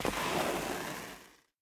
wallSlide.ogg